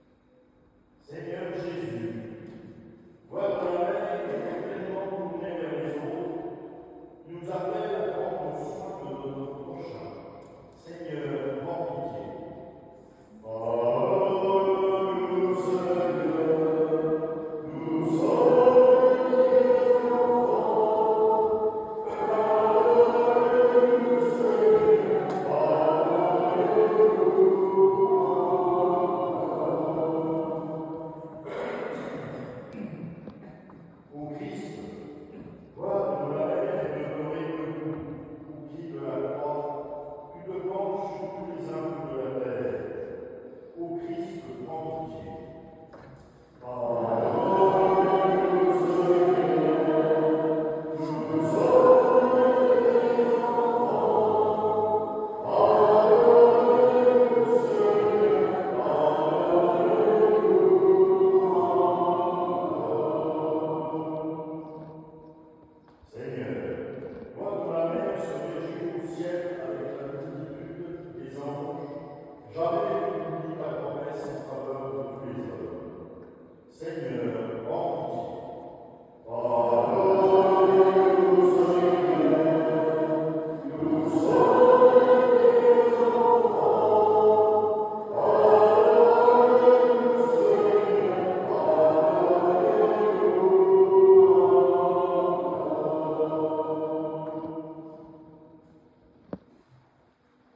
Une messe a eut lieu dans cette église le 15/08/2025 à 10H30
un cinquantaine de fidèles ont participé à cette office